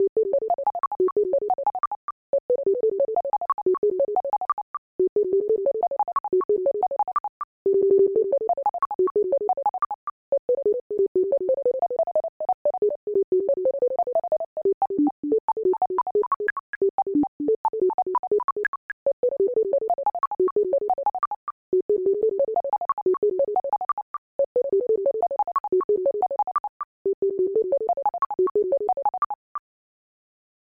be warned like most ofthis shit is just dnb/jungle